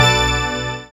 SYN DANCE0EL.wav